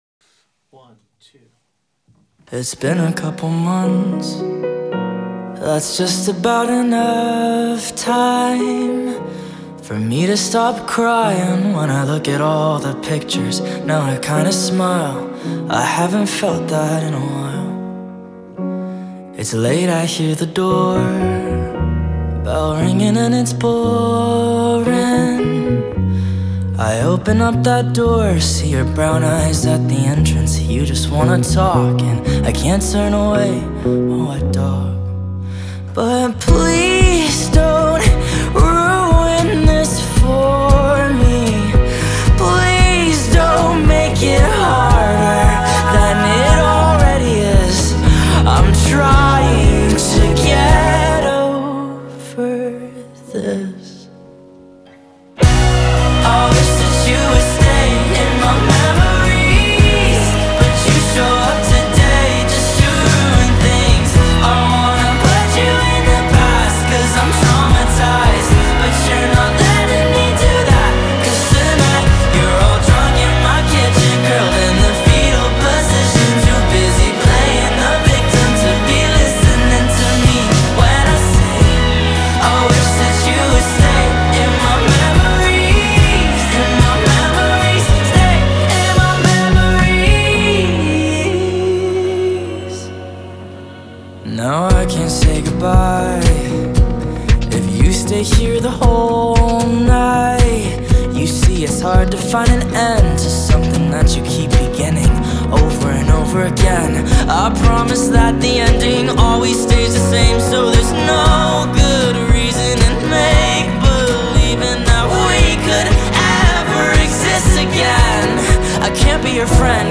موزیک خارجی
دانلود آهنگ سبک پاپ